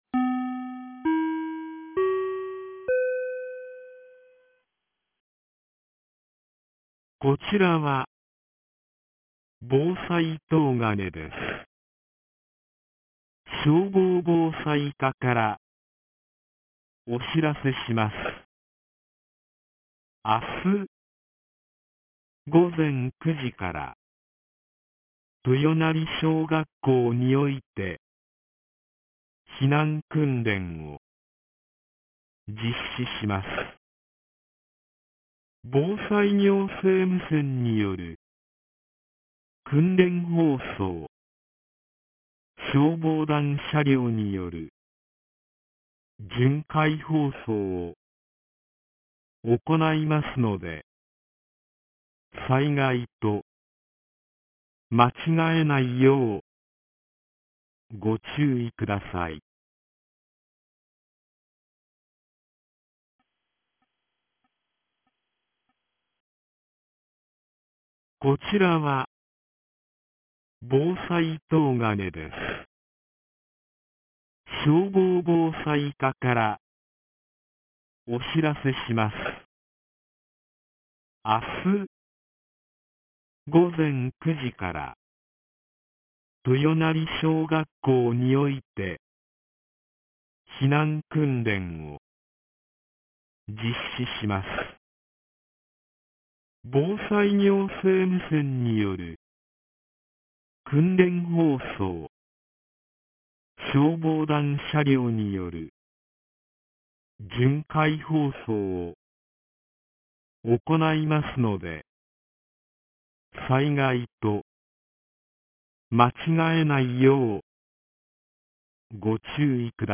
2025年10月31日 16時02分に、東金市より防災行政無線の放送を行いました。